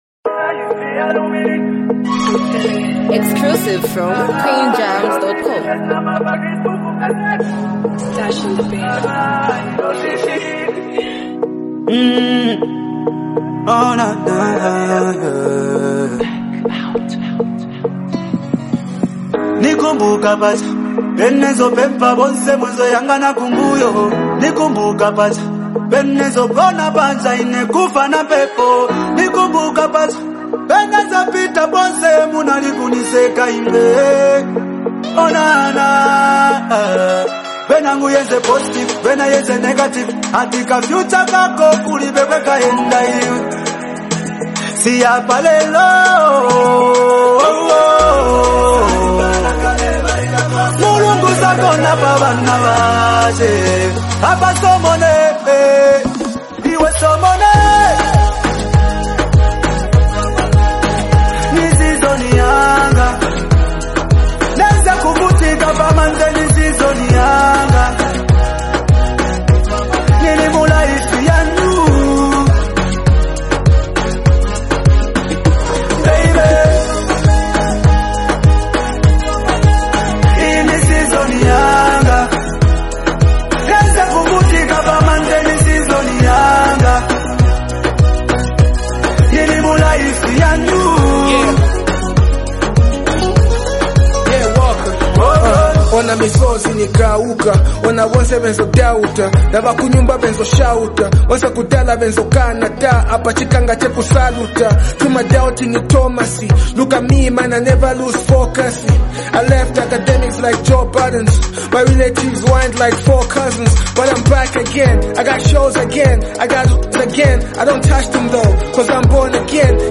confident and celebratory anthem